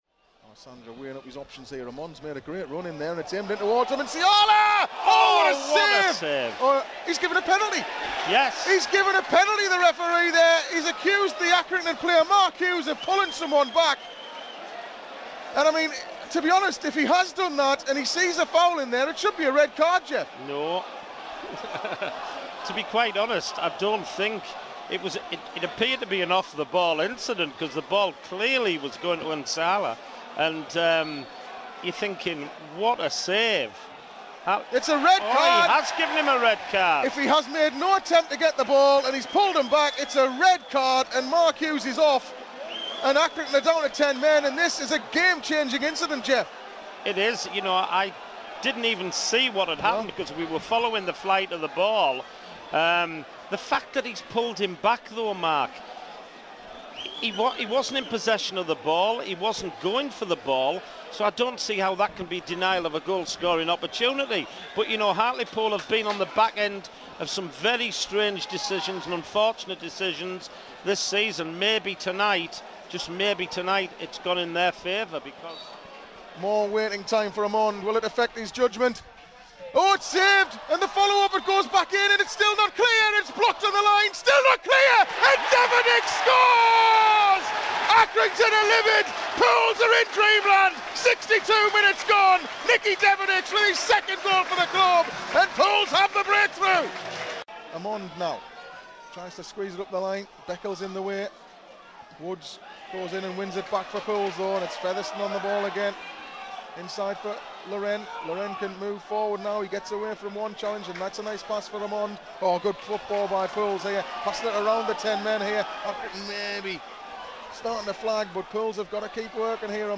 Listen back to how the goals from Tuesday's win over Accrington sounded as they went in live on Pools PlayerHD.